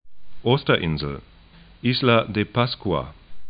'o:stɐ-ɪnzl
'i:zla de 'paskŭa